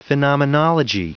Prononciation du mot phenomenology en anglais (fichier audio)